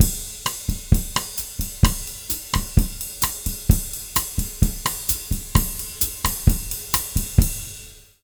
130BOSSA02-R.wav